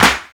07_Clap_13_SP.wav